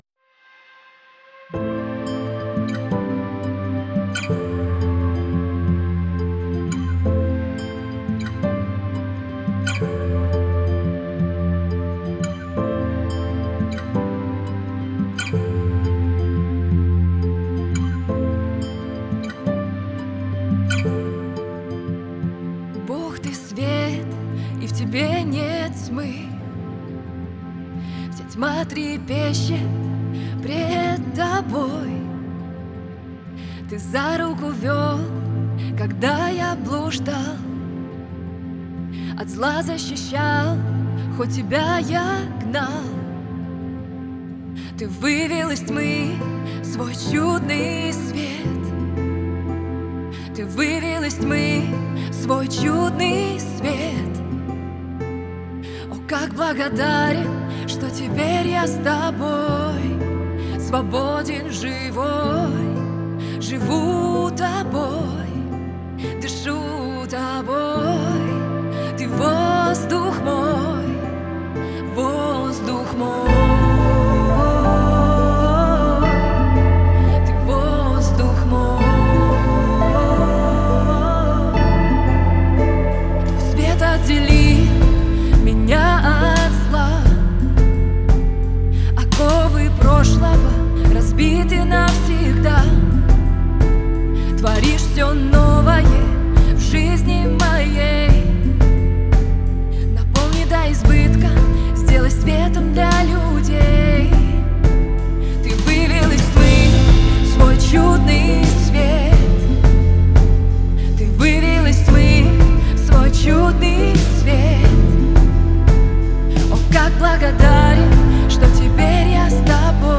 412 просмотров 223 прослушивания 12 скачиваний BPM: 174